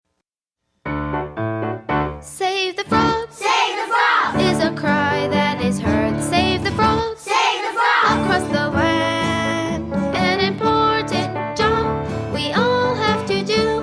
Children's Song About Frogs